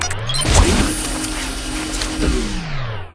battlesuit_remove.ogg